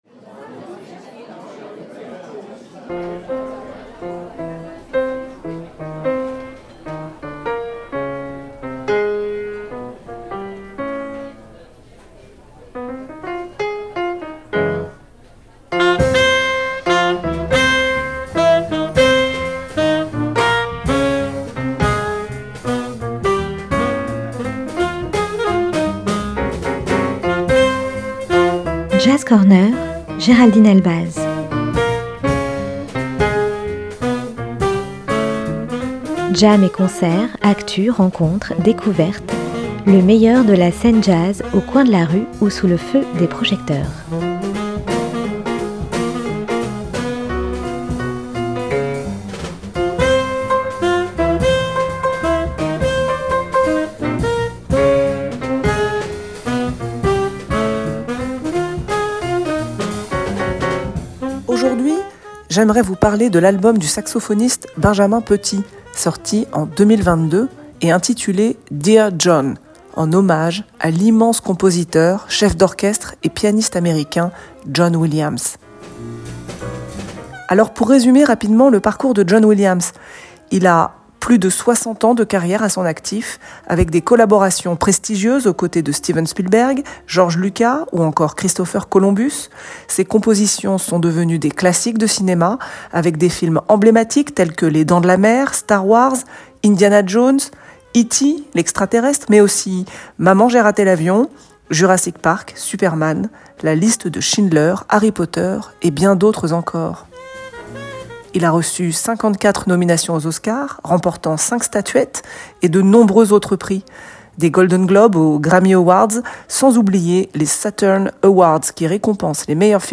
JAZZ CORNER, lundi et jeudi à 13h et 18h.